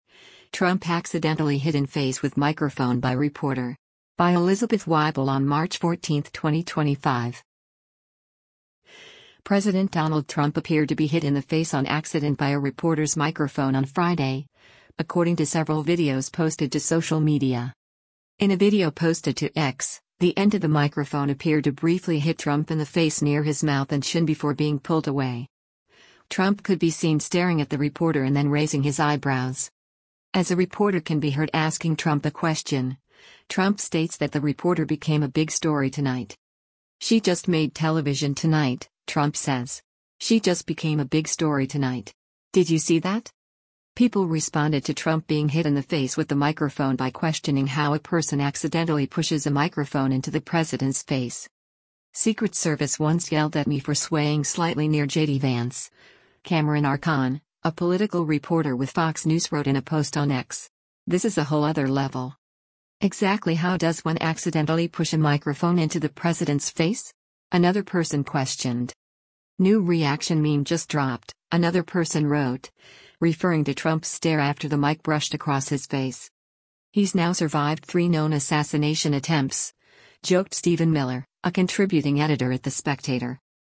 As a reporter can be heard asking Trump a question, Trump states that the reporter “became a big story tonight.”